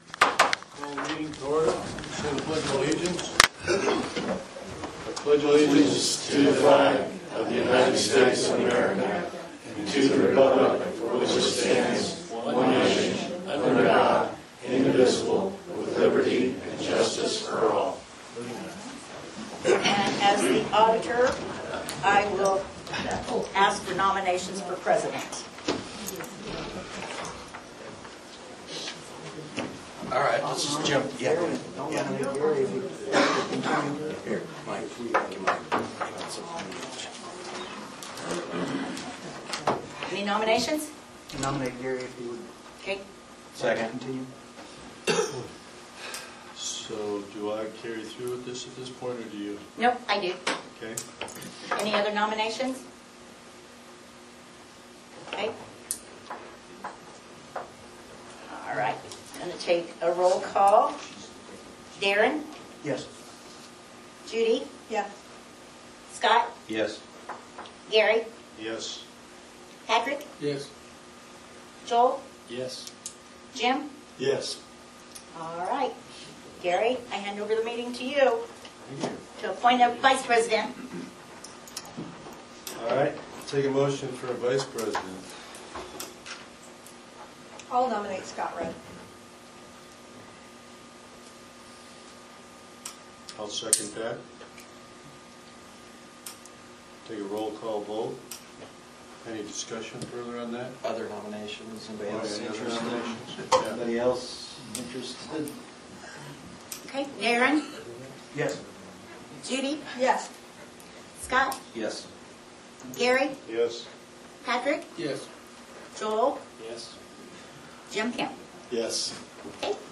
County Council Meeting Notes, Feb 18, 2025.